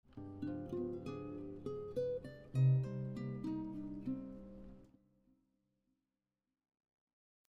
Harmonic minor scale lick 3